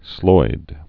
(sloid)